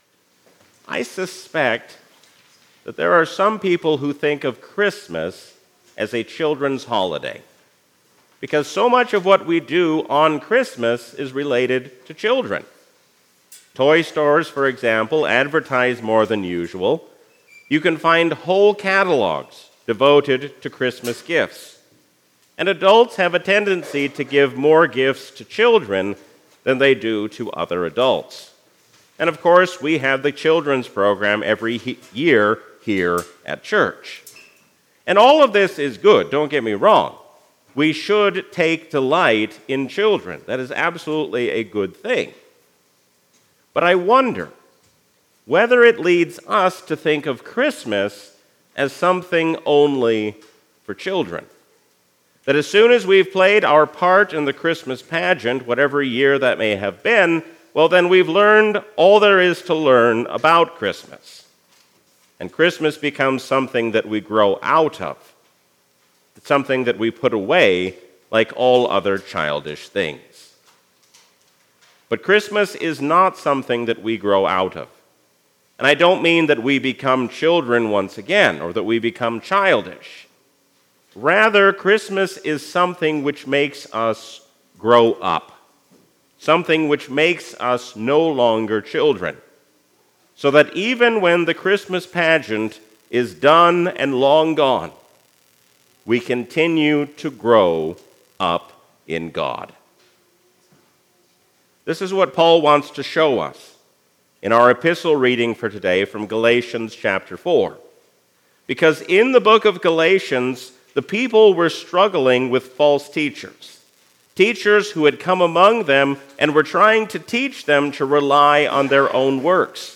A sermon from the season "Christmas 2024." Jesus is the reason for the season because He has come to be our Brother and our Savior.